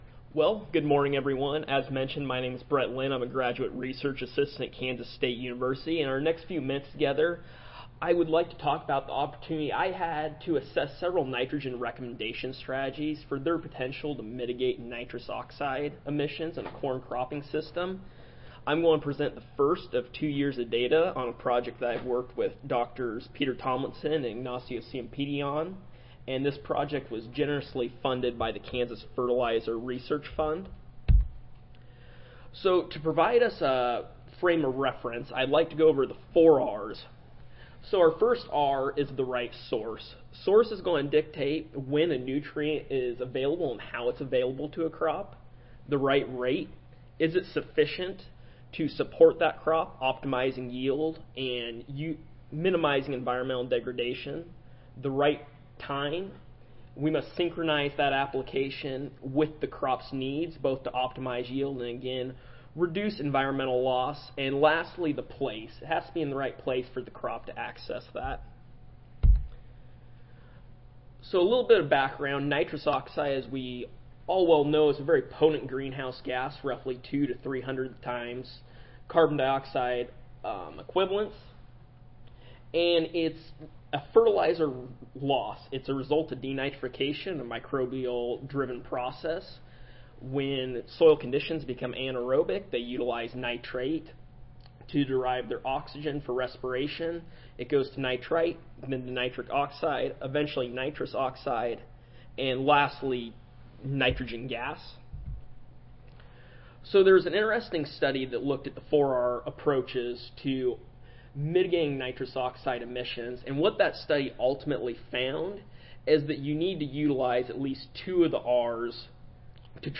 Kansas State University Audio File Recorded Presentation